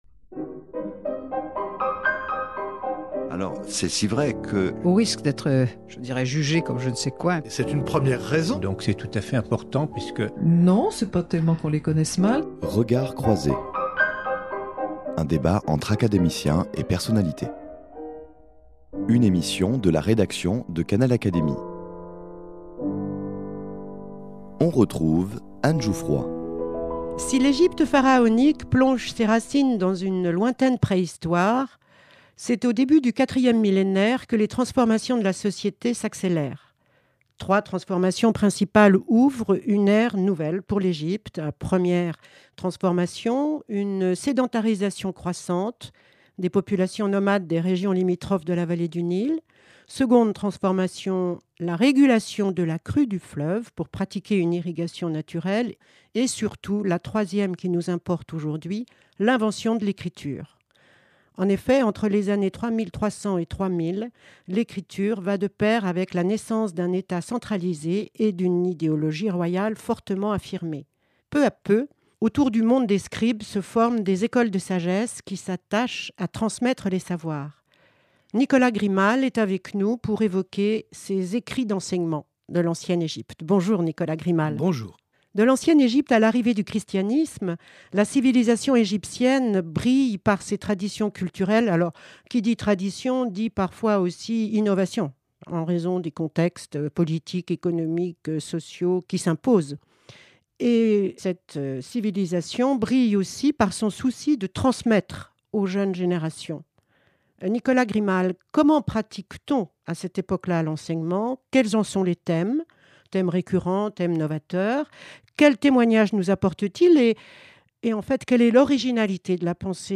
Peu à peu, autour du monde des scribes, se forment des Écoles de Sagesse qui s’attachent à transmettre les savoirs. L’égyptologue Nicolas Grimal évoque les « Enseignements » - ainsi nommés par les lettrés de l’époque - et l’originalité de la pensée égyptienne.